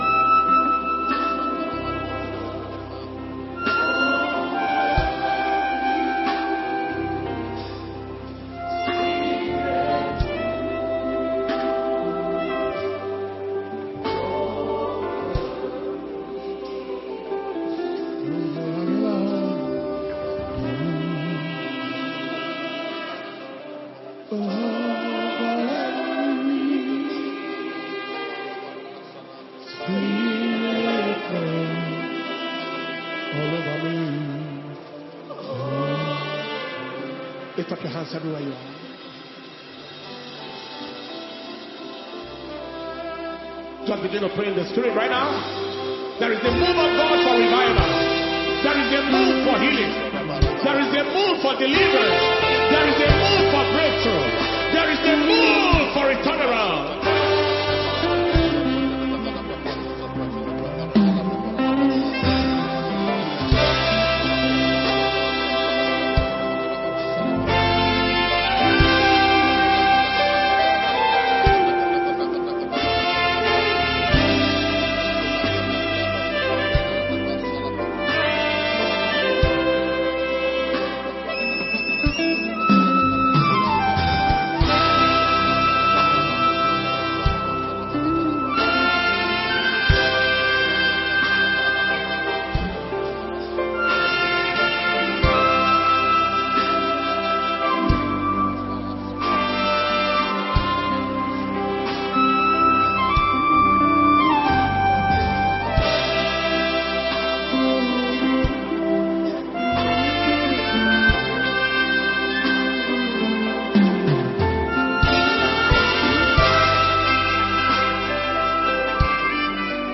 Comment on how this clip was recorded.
Special Healing And Deliverance Service